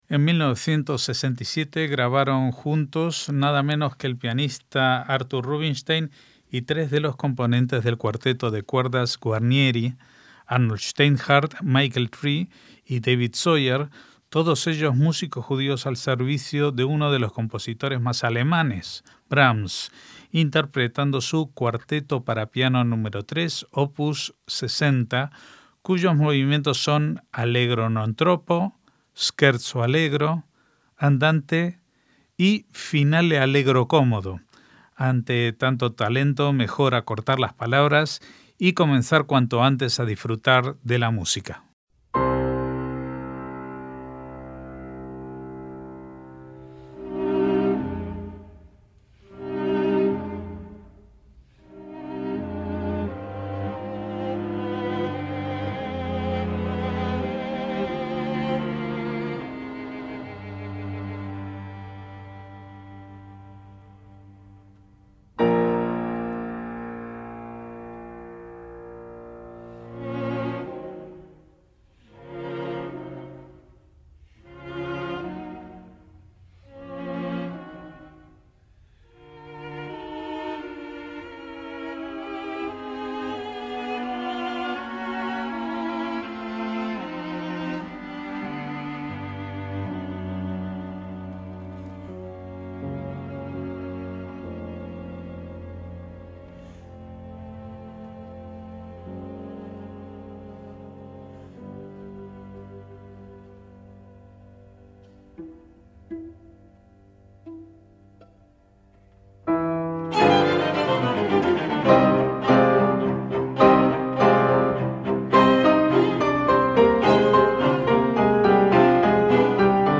MÚSICA CLÁSICA
cuarteto de cuerdas